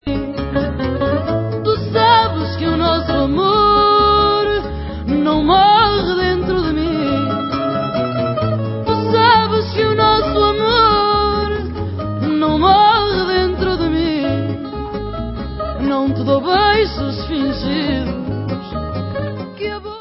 World/Fado